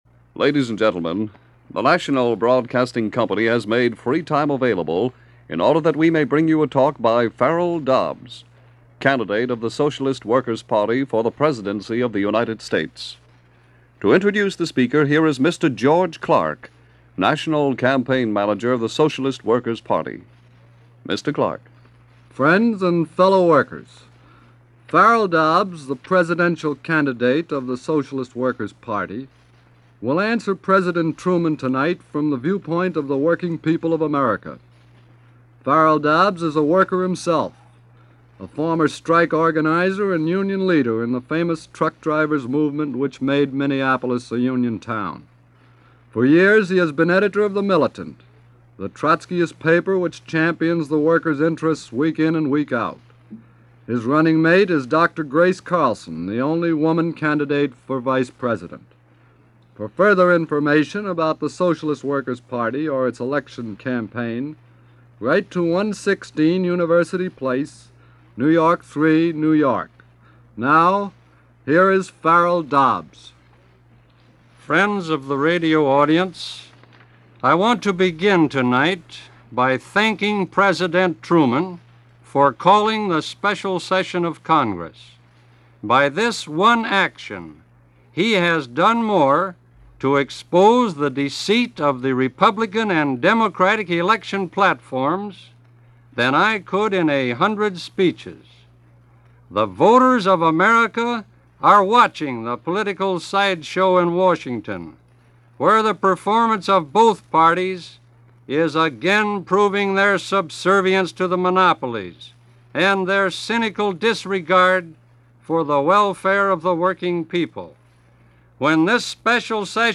Farrell Dobbs - Candidate for President, Socialist Workers Party Ticket in 1948 - delivering a Campaign address via NBC Radio - August 6, 1948.